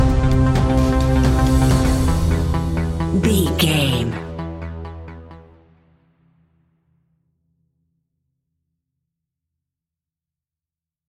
Industrial Percussive Stinger.
Aeolian/Minor
ominous
dark
eerie
synthesiser
drums
ticking
electronic music